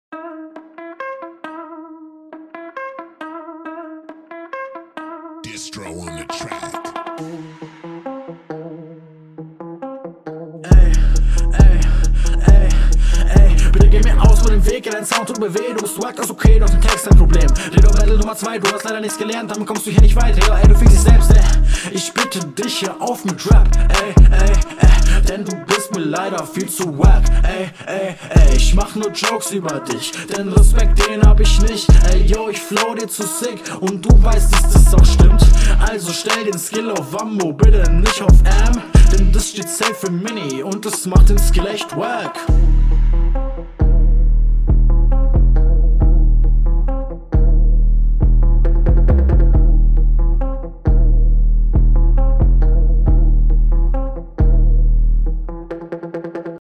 flow ok aber noch paar timing fehler text unterirdisch schlecht alles in allem weak